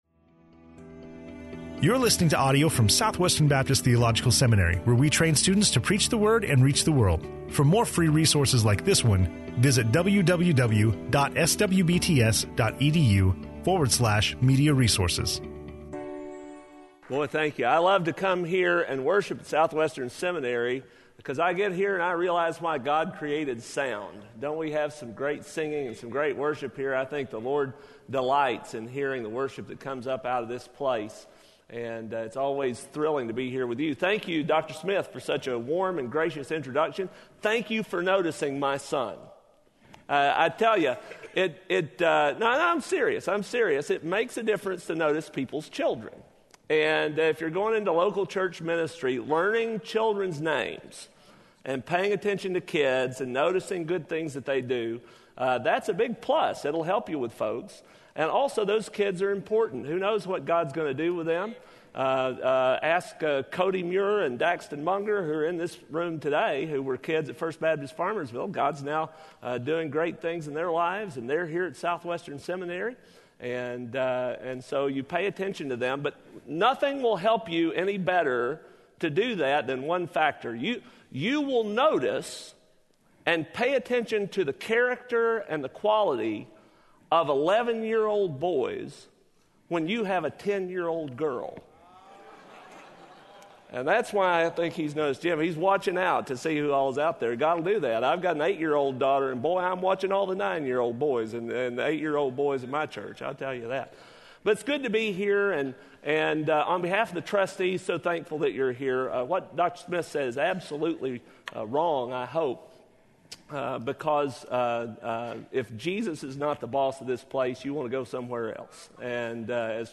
SWBTS Spring 2015 Chapel